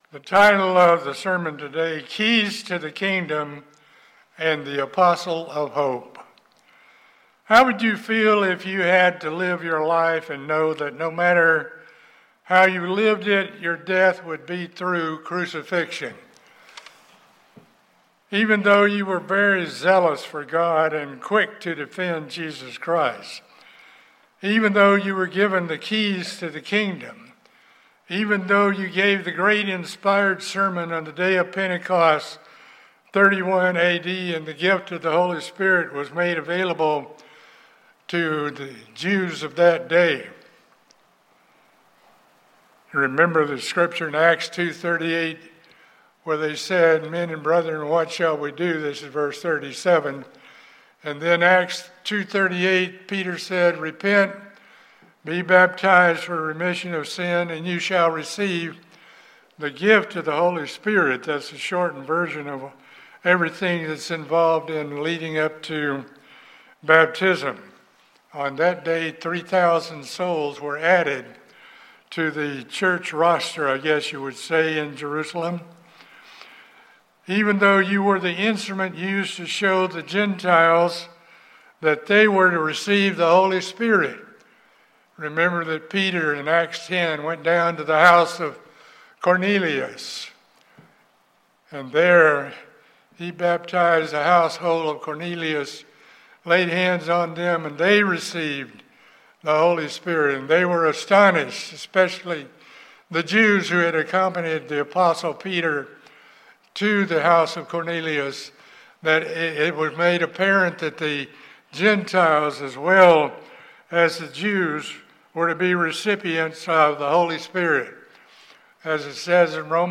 This sermon explores the faithful characteristics of Peter, the apostle of hope.